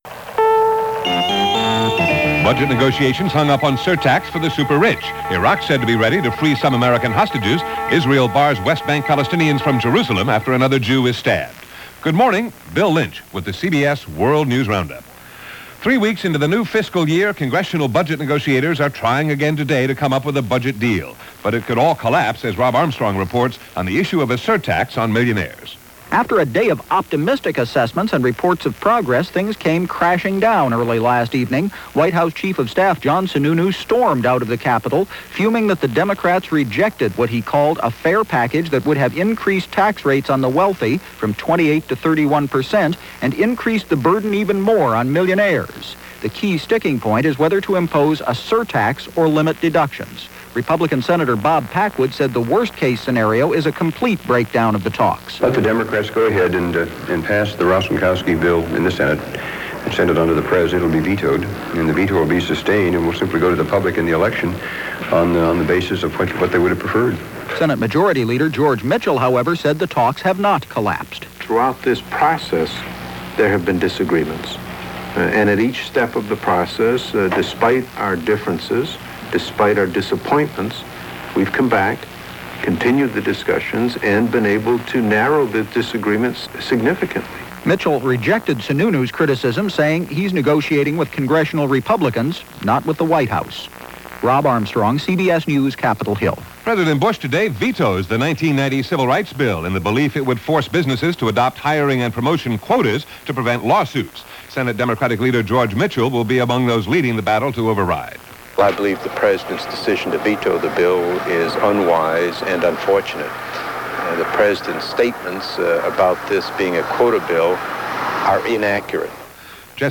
And that’s just a small slice of what went on, this rather hectic October 22, 1990 as reported by the CBS World News Roundup.